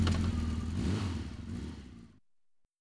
motorBikeEngineEnd.wav